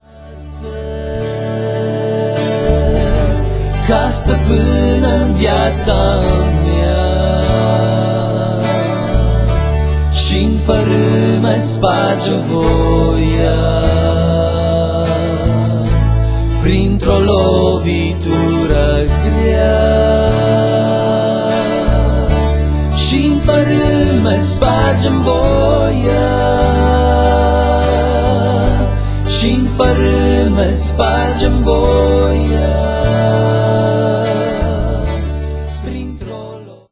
aranjamentul instrumental